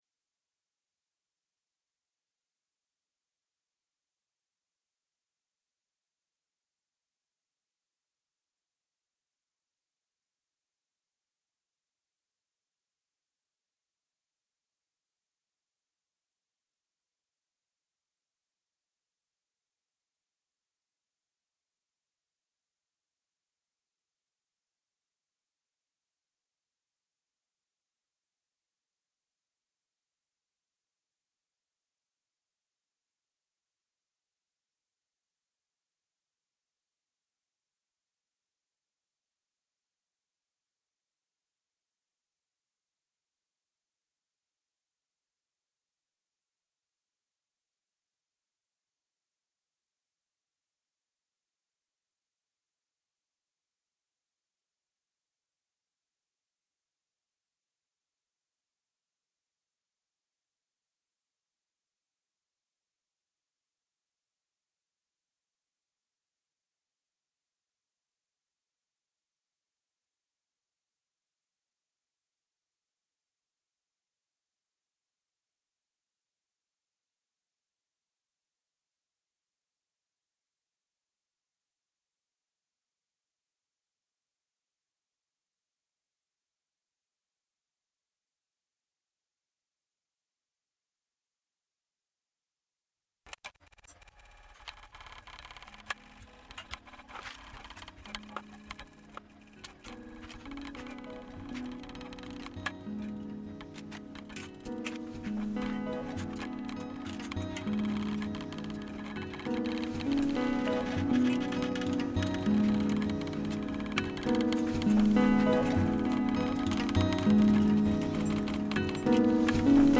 Transmission Arts & Experimental Sounds